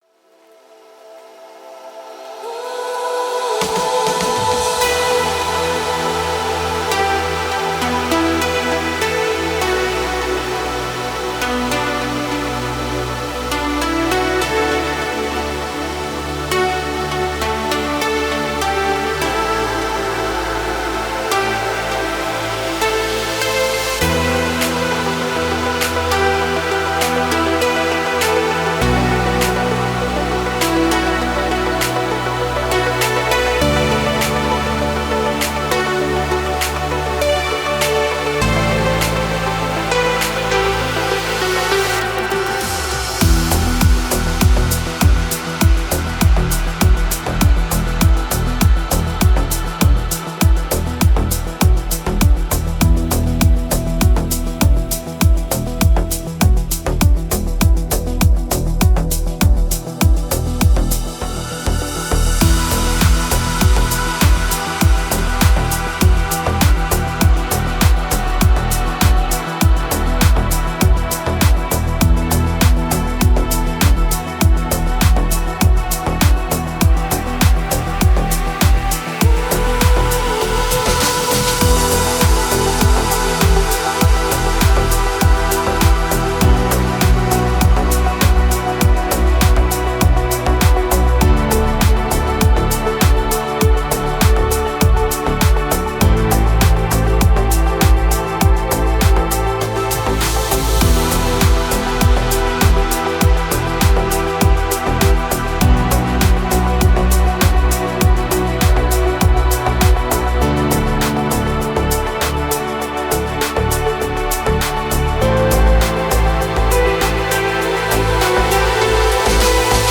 Deep House музыка
дип хаус